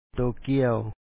tookìaw Tokyo